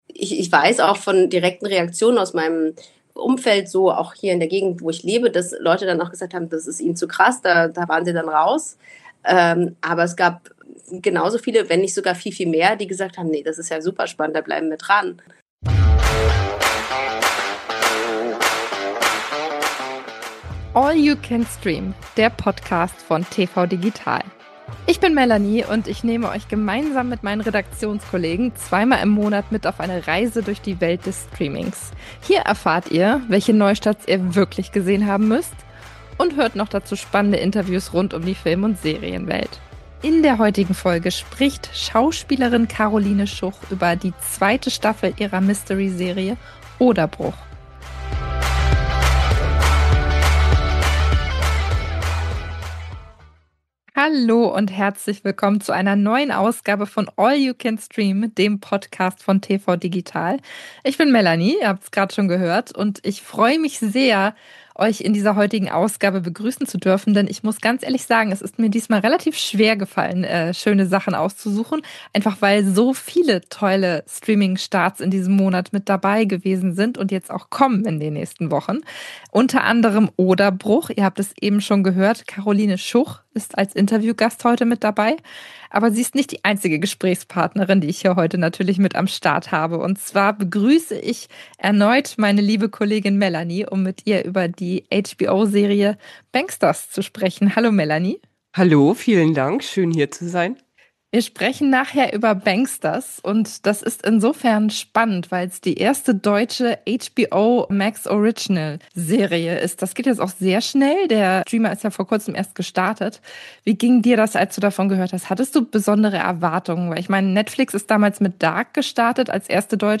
Die größten Streaming-Highlights von Mitte bis Ende Februar. UND: Exklusives Interview mit Schauspielerin Karoline Schuch über die zweite Staffel ihrer Mystery-Serie "Oderbruch" (ARD-Mediathek).